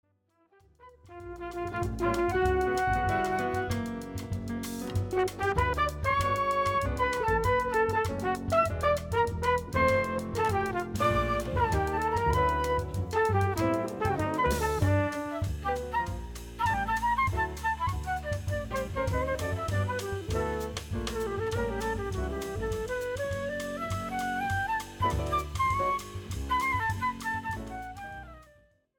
and vibrant Latin and Brazilian jazz melodies
Guitar
Piano
Bass
Drums